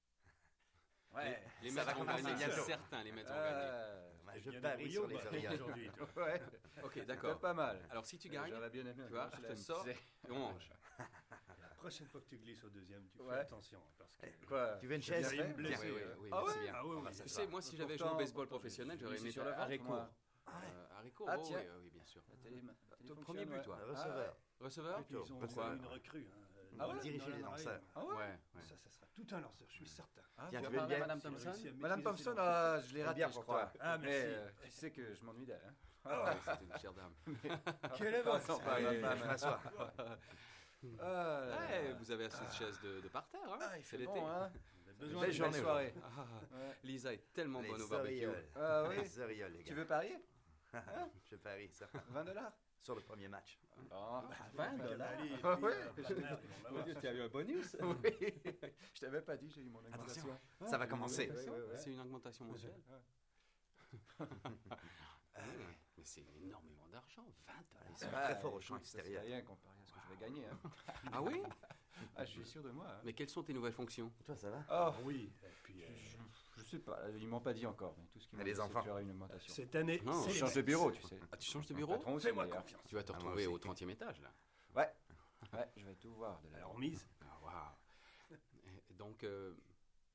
描述：室内声乐（法国）氛围：派对沃拉
标签： 沃拉 定位资产 人声的氛围 室内
声道立体声